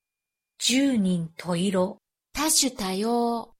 Prononciation-de-junin-toiro-et-tashu-tayo.mp3